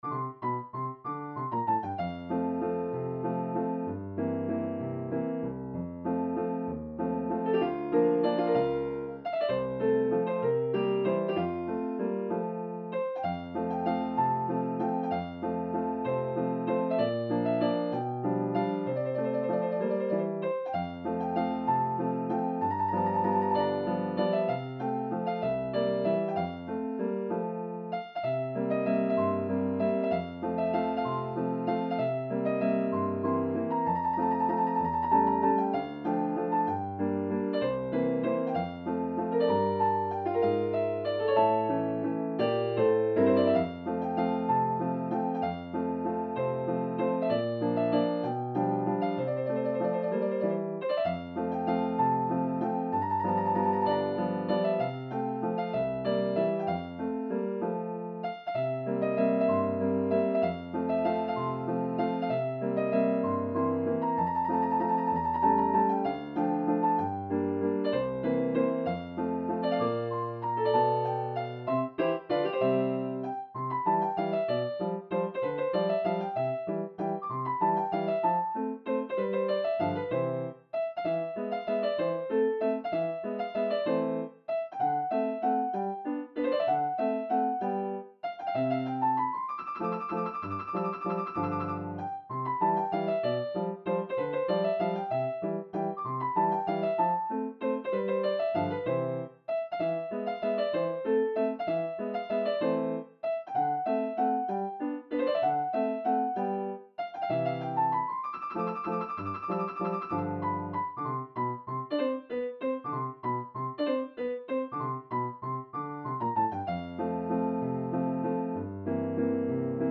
Bagatelle num.2 in FM - Piano Music, Solo Keyboard - Young Composers Music Forum
Hi, here a classic bagatelle. I wrote the main theme some years ago and arranged it for piano in this quarantine days.